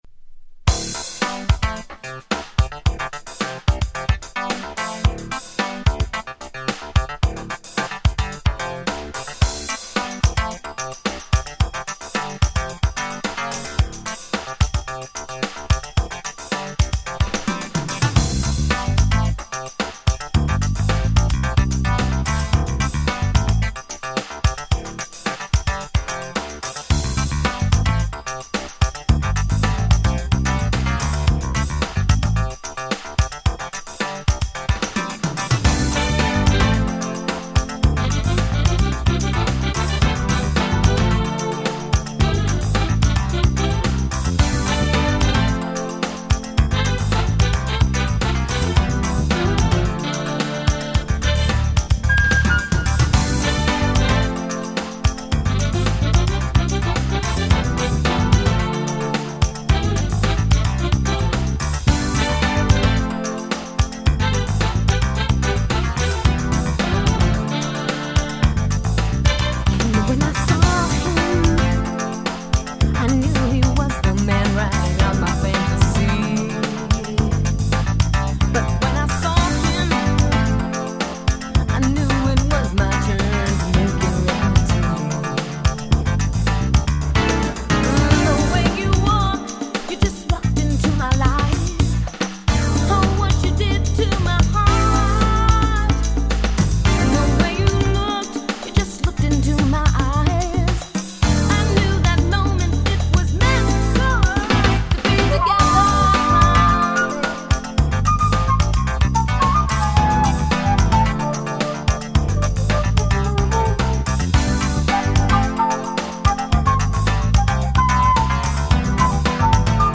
95' マイナーJapanese R&B !!
こちらは何とオシャレでJazzyな踊れるDance Track !!